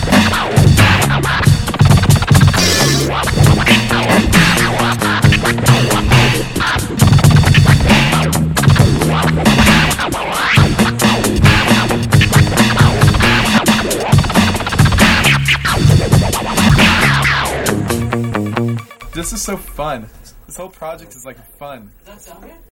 Категория: Rock